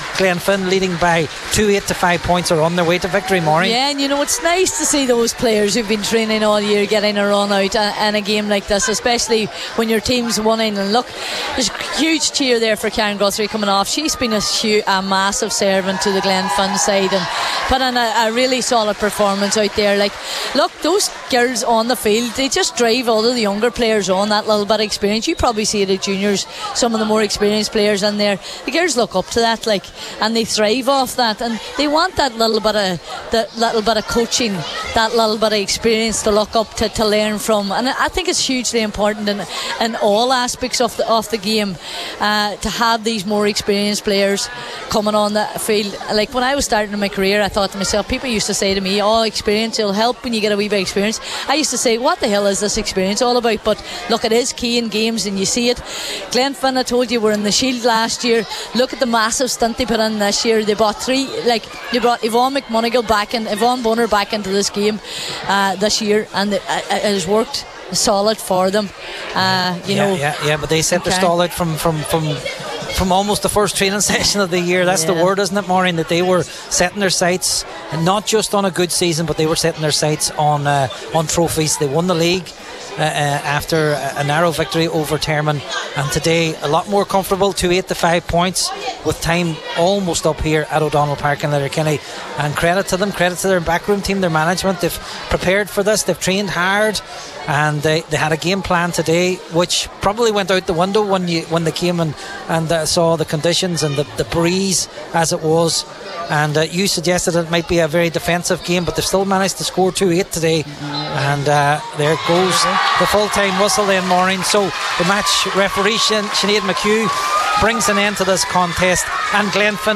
live at full time for Highland Radio Sport…